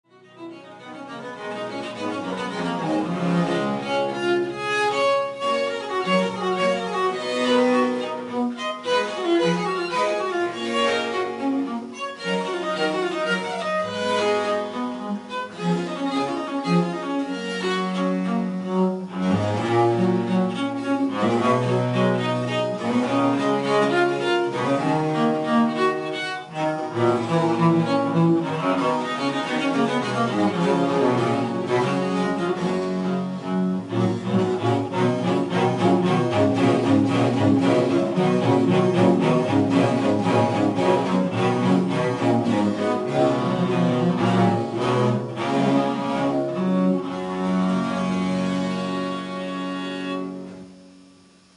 Folies de Marais en l'Eglise de Marcq (78 Thoiry) le 14 octobre 2006